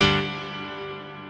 piano11_1.ogg